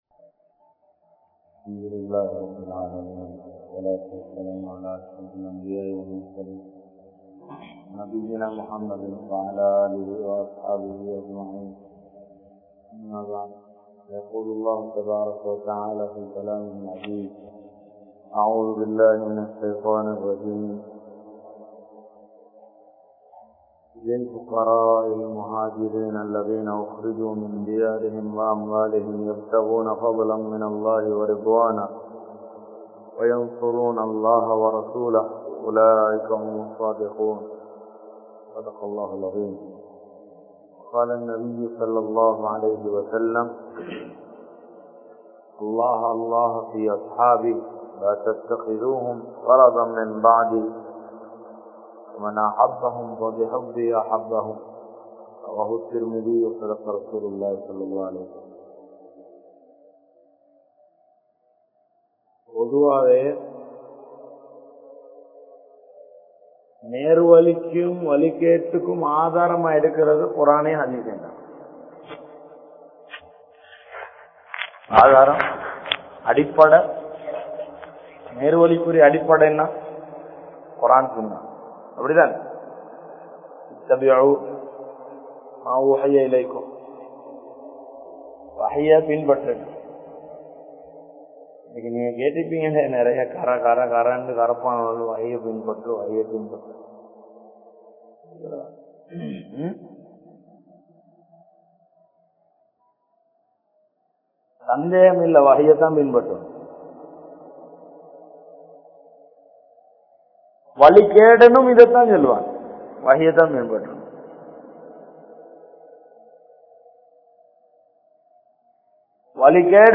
Shahabaakkalin Sirappuhla (ஸஹாபாக்களின் சிறப்புகள்) | Audio Bayans | All Ceylon Muslim Youth Community | Addalaichenai
Majmaulkareeb Jumuah Masjith